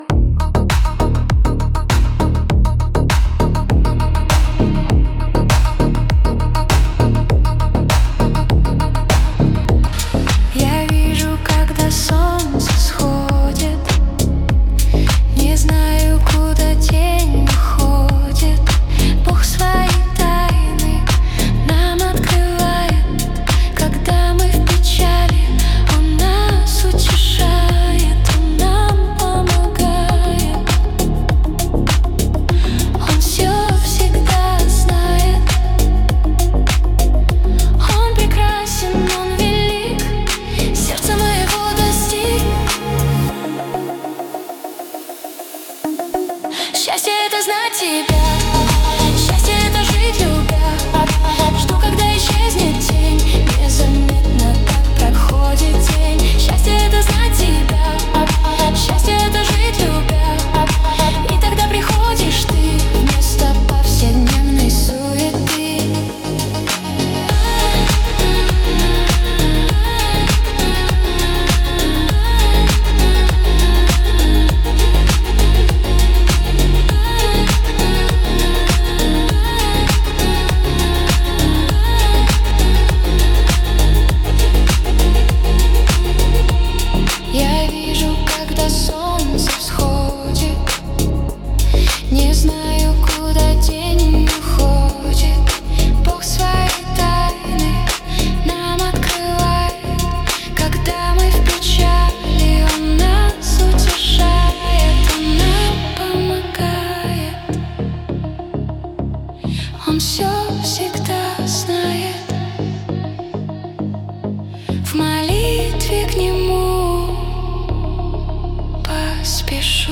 песня ai
29 просмотров 161 прослушиваний 9 скачиваний BPM: 101